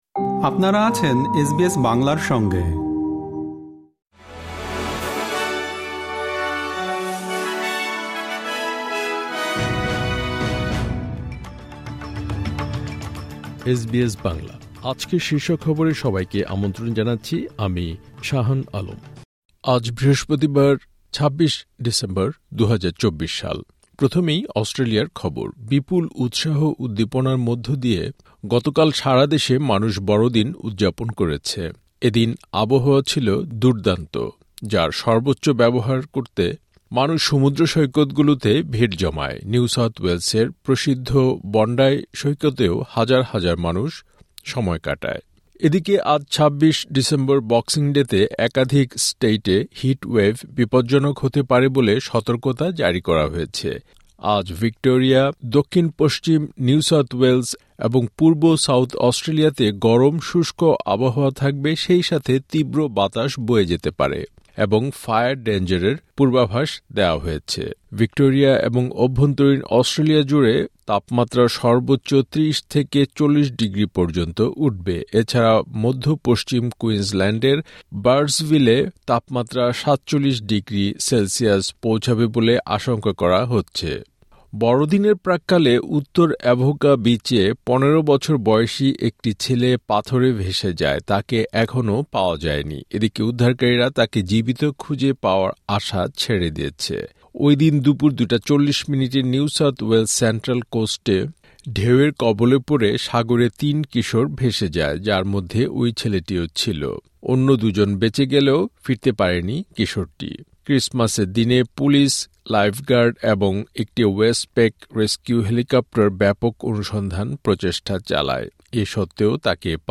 আজকের শীর্ষ খবর বিপুল উৎসাহ উদ্দীপনার মধ্যে দিয়ে গতকাল সারা দেশে মানুষ বড়দিন উদযাপন করেছে। বাংলাদেশের রাজধানী ঢাকার সেগুনবাগিচায় বাংলাদেশ সচিবালয়ের আজ বৃহস্পতিবার স্থানীয় সময় সকাল সাতটায় সাত নম্বর ভবনে আগুন। বক্সিং ডে ক্রিকেটে মেলবোর্নের এমসিজি-তে আজ মুখোমুখি হচ্ছে অস্ট্রেলিয়া ও ভারত।